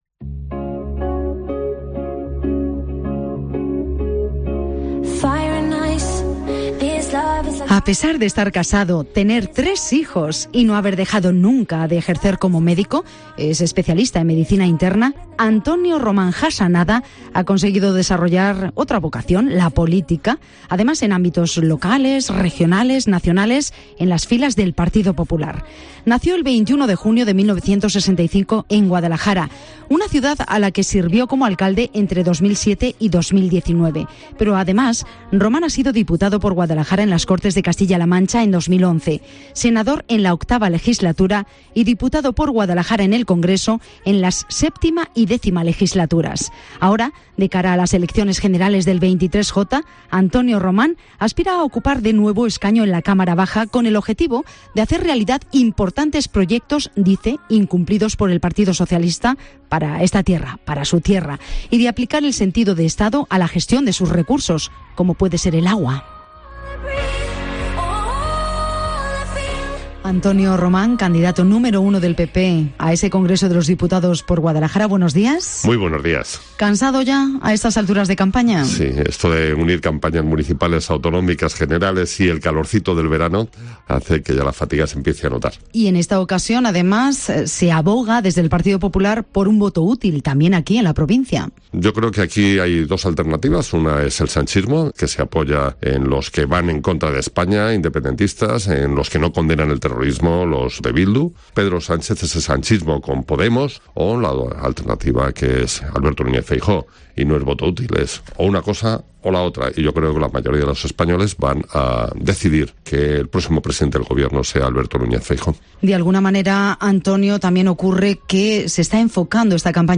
El candidato número 1 del Partido Popular al Congreso de los Diputados por Guadalajara, Antonio Román, ha pasado por los micrófonos de Mediodía COPE Guadalajara, en este sexto día de campaña electoral de cara a las Elecciones Generales del 23J.